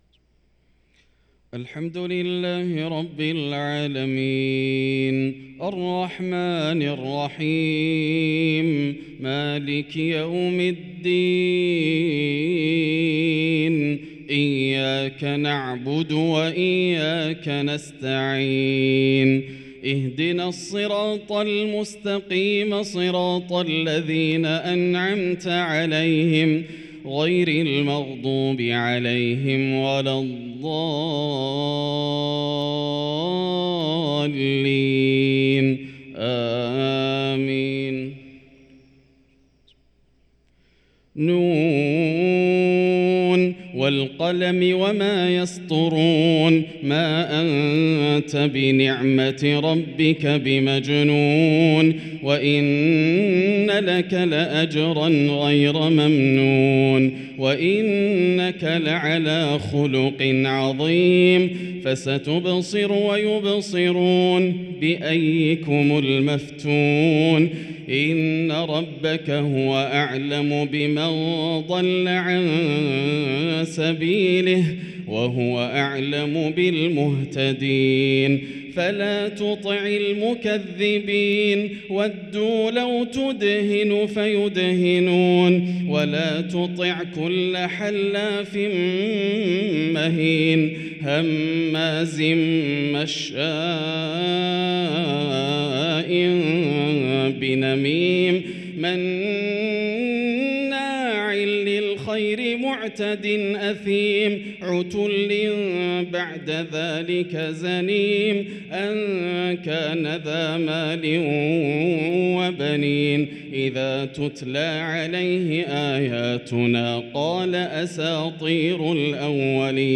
صـلاة الظهر الشيخان ماهر المعيقلي وعـبـد الباري الثبيتي